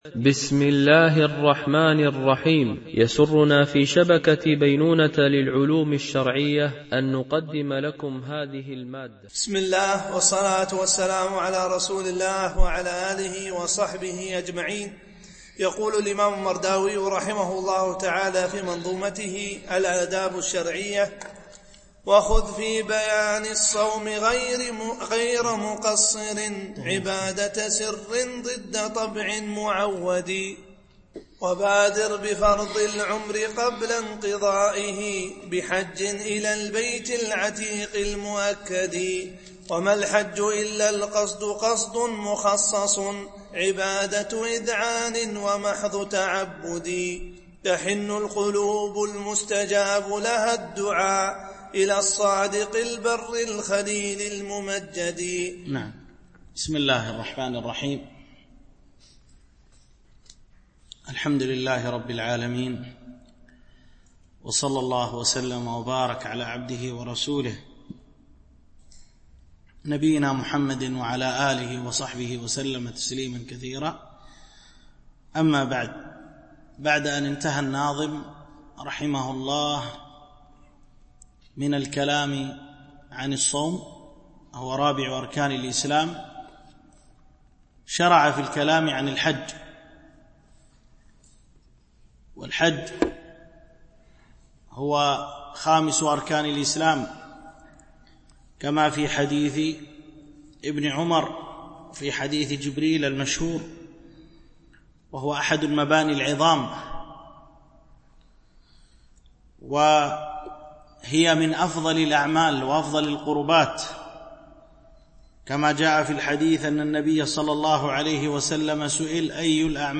شرح منظومة الآداب الشرعية – الدرس42 ( الأبيات 638-658 )